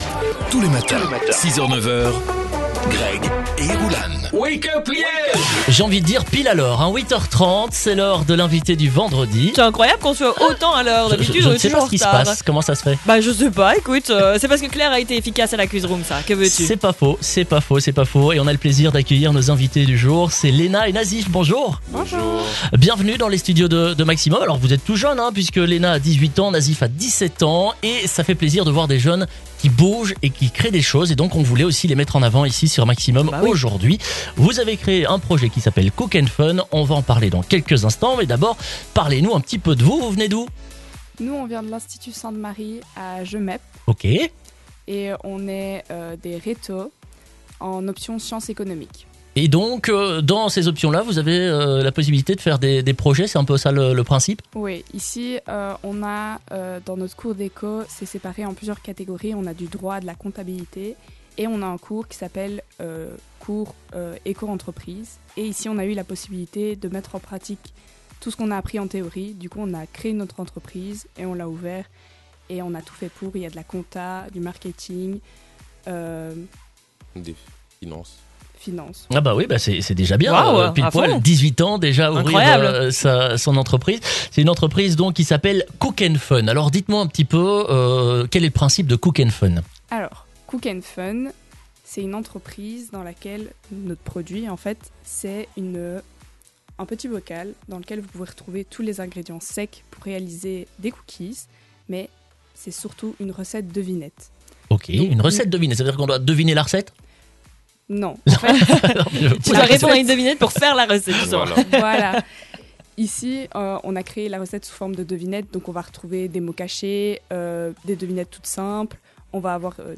Ce matin, des étudiants qui se sont lancé dans un projet entrepreunarial, "Cook&Fun", étaient les invités du Wake Up Liège ! Focus sur leur beau projet dans ce podcast !